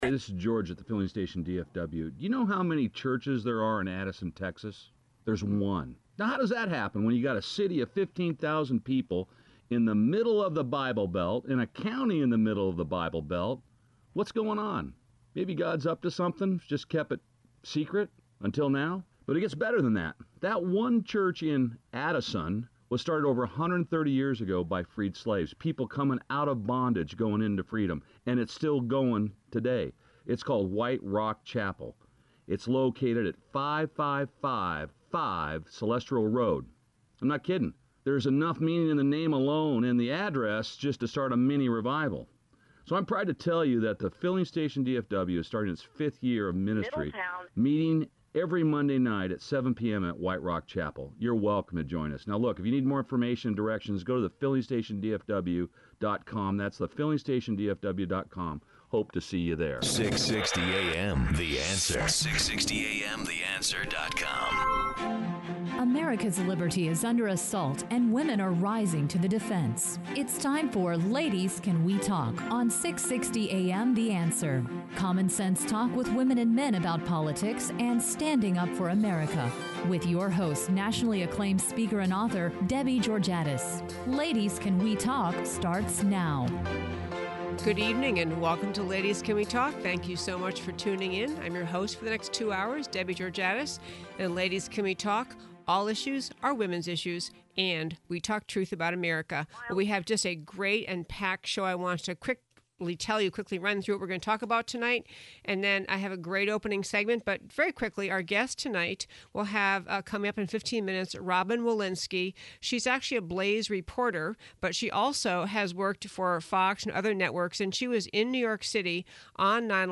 Right and Wrong Lessons of 9/11; Interview